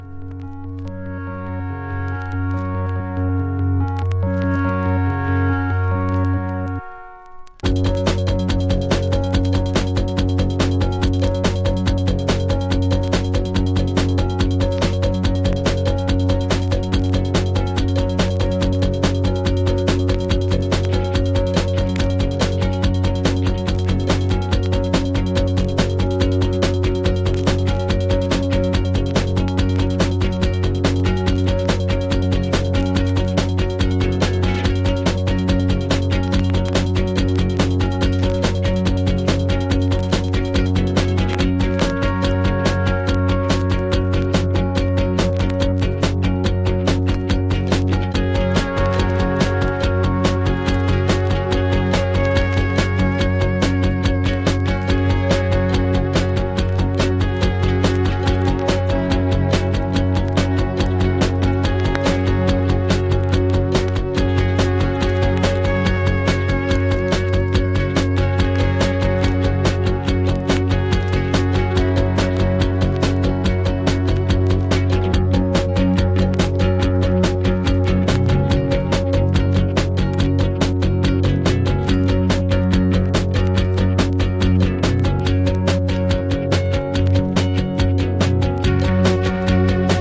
プログレッシヴなギターワークが素晴らしナンバー！！